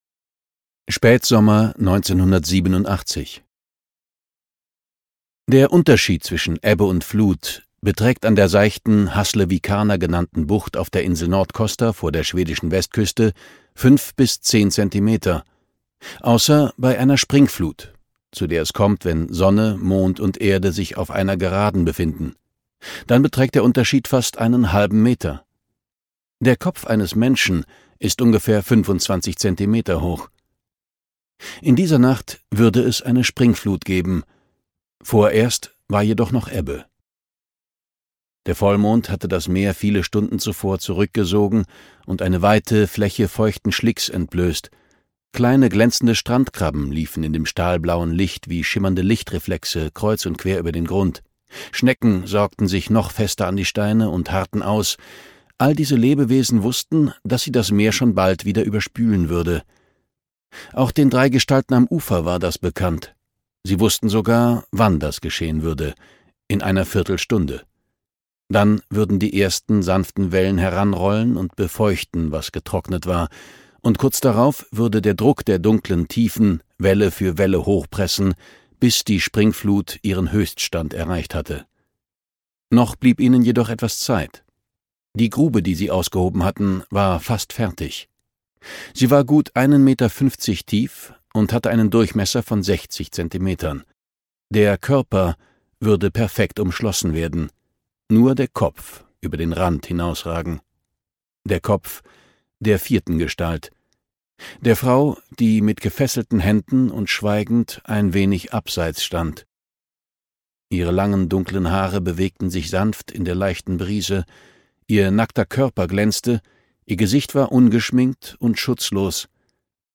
Audio knihaDie Springflut (DE)
Ukázka z knihy